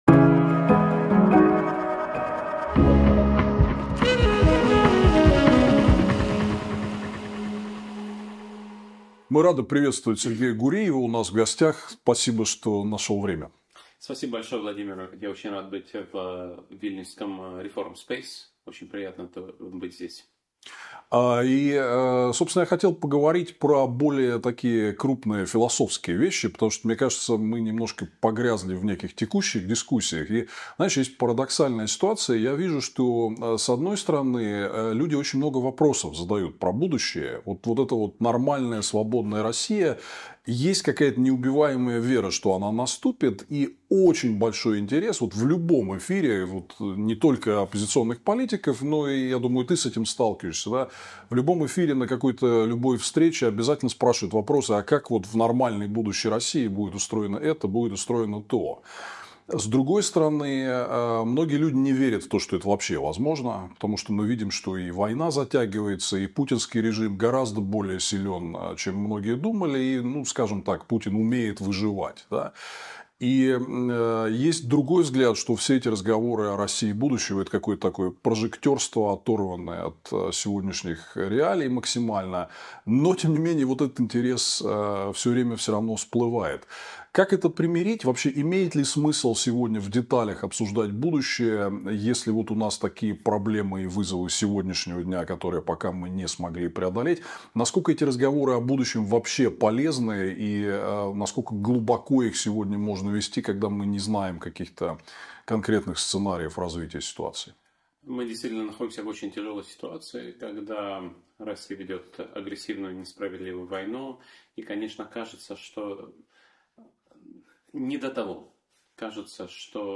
Интервью 17 декабря 2024 «Диалоги с Миловым» и Сергеем Гуриевым: Переход от диктатуры к демократии возможен.